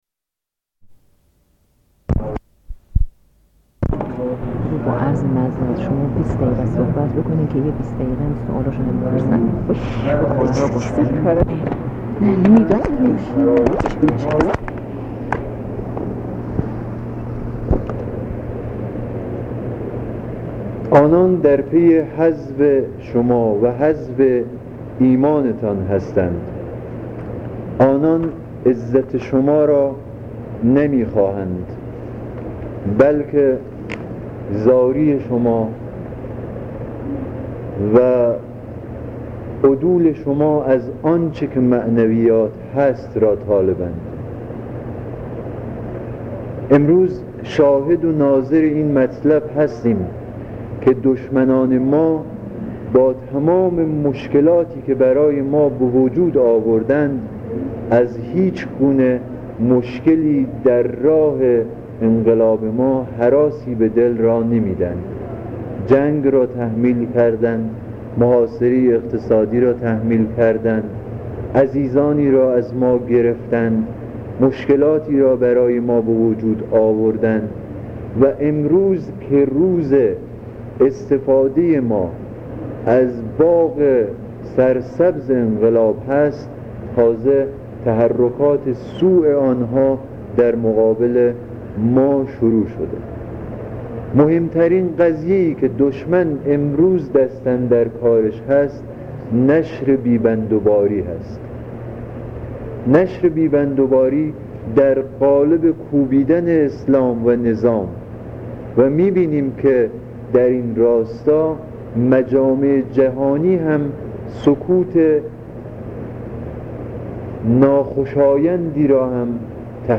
صدای ماندگار/ سخنرانی